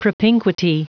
Prononciation du mot propinquity en anglais (fichier audio)
Prononciation du mot : propinquity